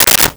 Switchboard Telephone Receiver Put Down 01
Switchboard Telephone Receiver Put Down 01.wav